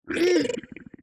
Minecraft Version Minecraft Version latest Latest Release | Latest Snapshot latest / assets / minecraft / sounds / mob / strider / happy5.ogg Compare With Compare With Latest Release | Latest Snapshot